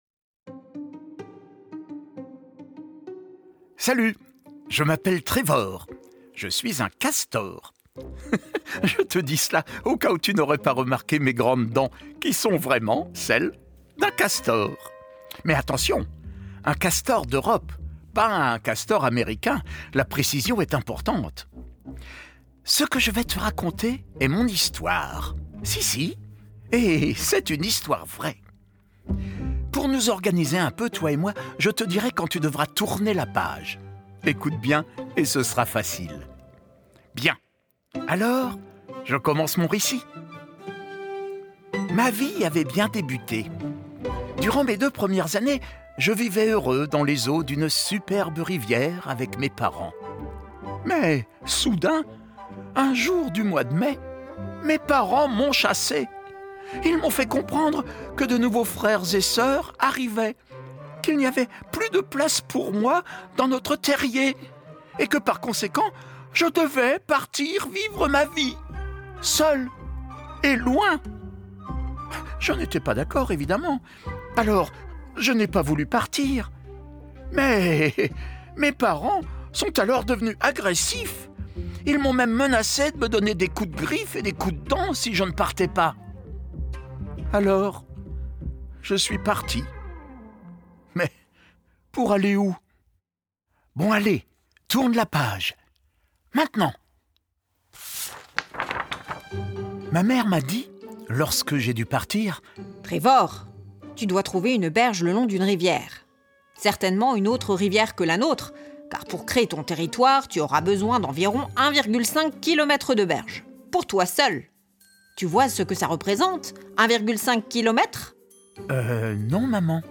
Trevor le Castor, un audio livre pour les enfants de 3 à 7 ans